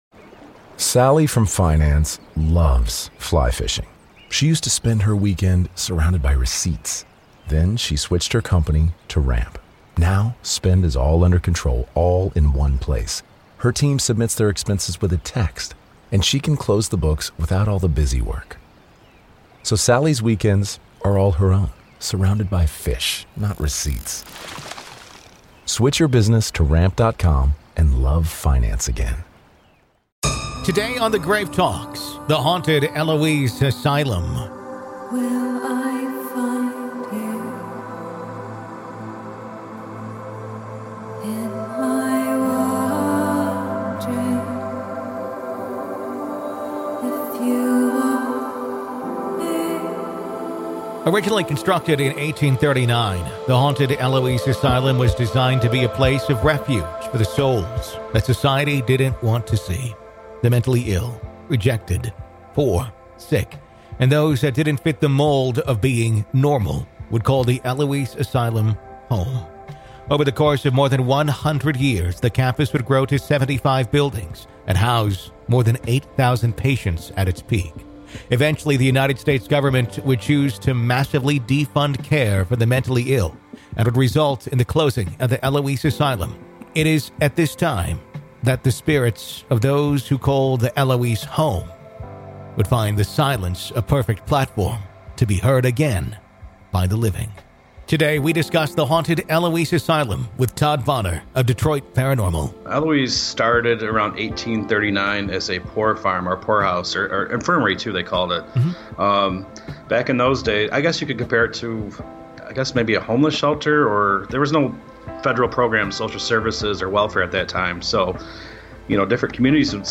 Join us as former staff, historians, and paranormal investigators revisit a place where the lights went out—but the stories never did.